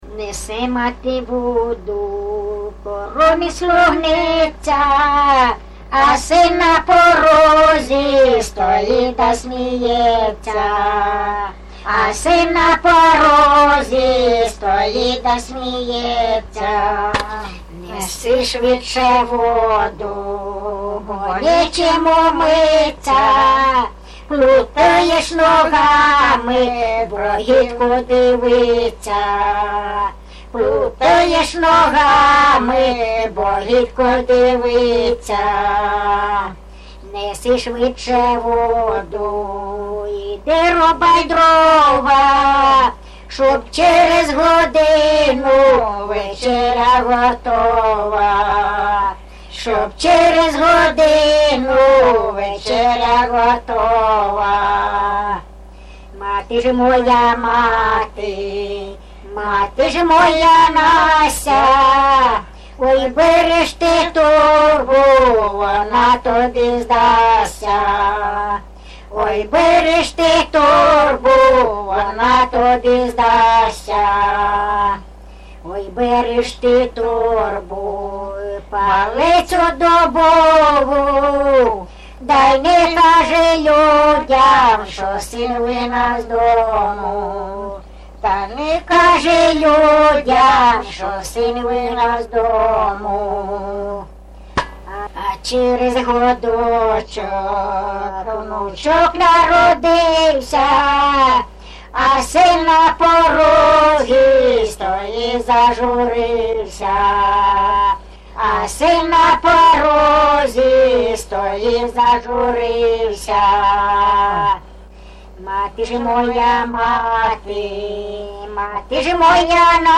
ЖанрБалади, Сучасні пісні та новотвори
МотивНещаслива доля, Родинне життя, Журба, туга